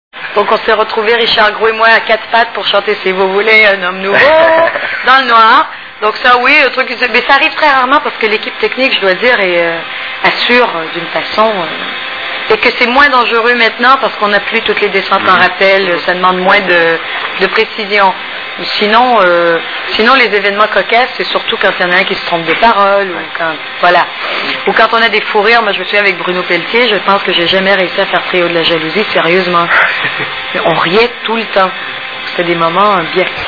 STARMANIA...Interview de Jasmine Roy !!!
( Casino de Paris, Hall d’entrée, 06/02/2000 )